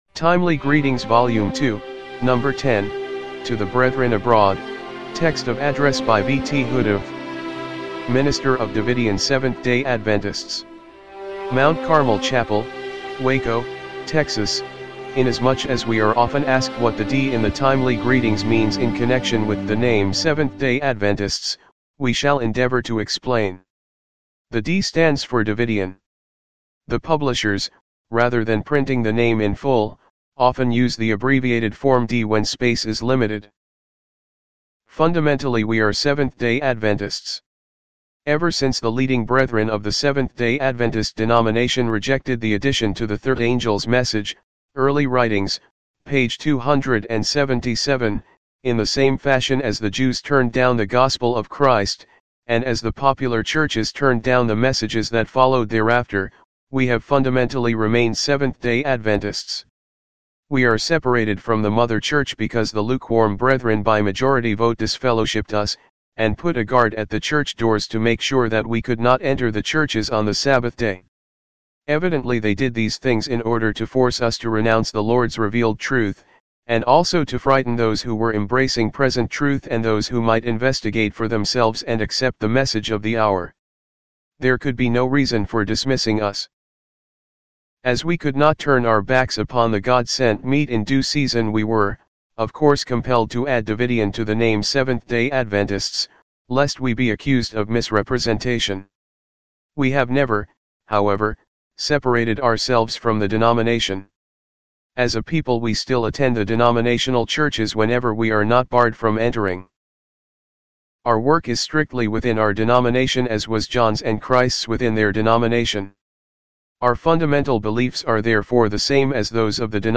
TEXT OF ADDRESS